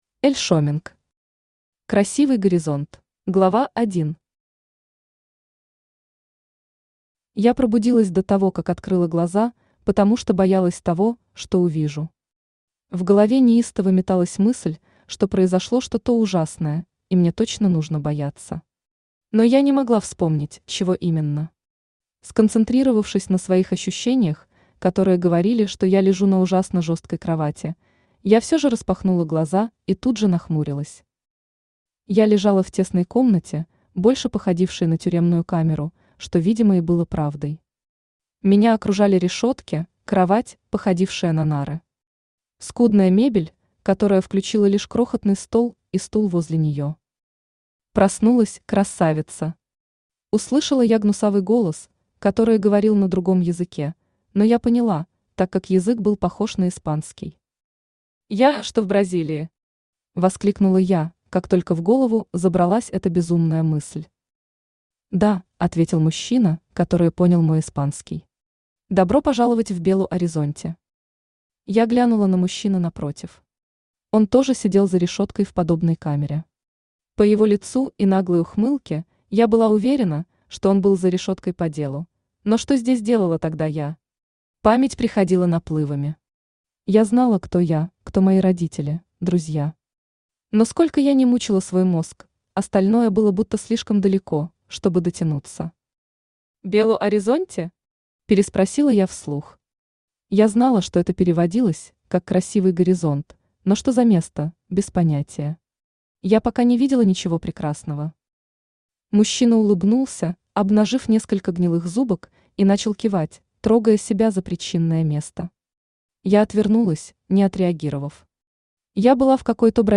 Аудиокнига Красивый горизонт | Библиотека аудиокниг
Aудиокнига Красивый горизонт Автор Эль Шеминг Читает аудиокнигу Авточтец ЛитРес.